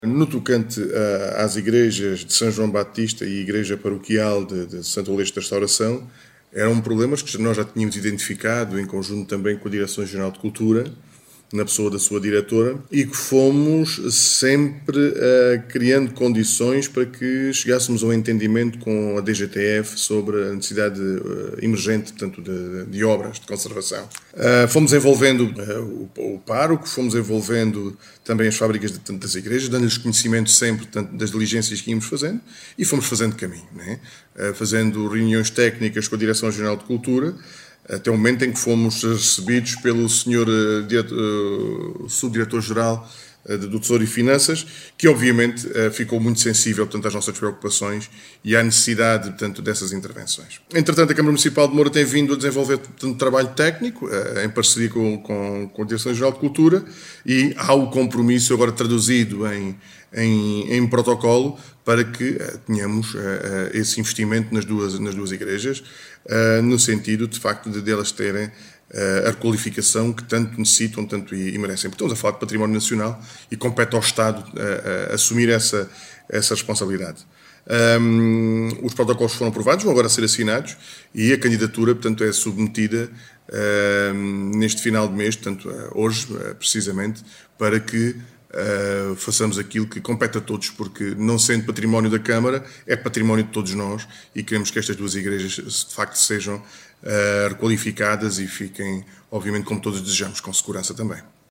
Declarações-Presidente-CM-Moura.mp3